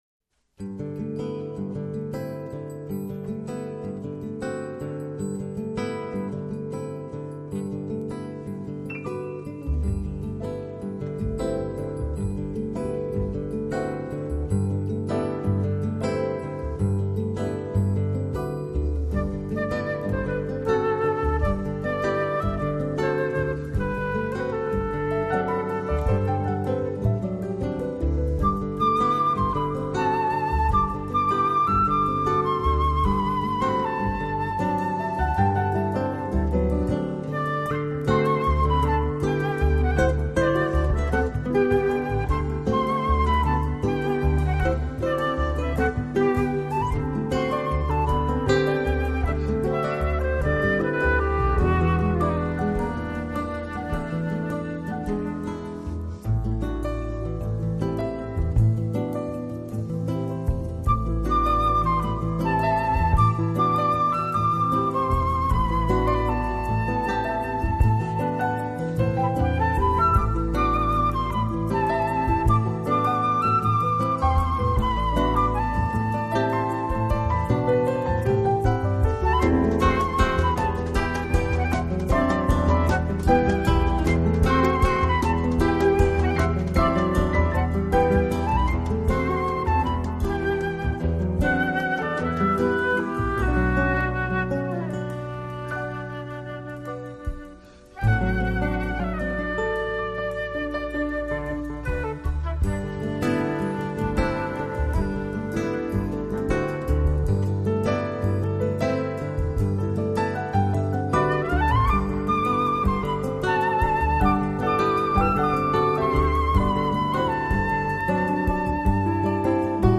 熔古典、爵士于一体的专辑
时还有一支25人的弦乐团助阵。